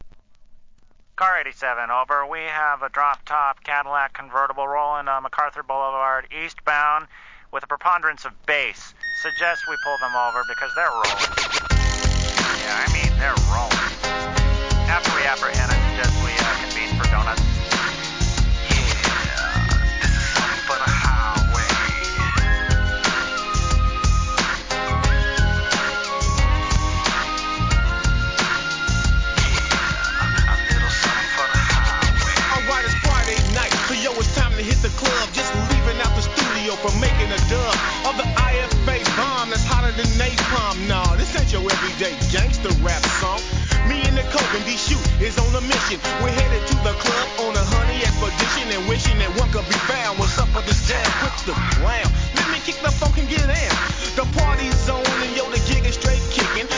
G-RAP/WEST COAST/SOUTH
哀愁G!!!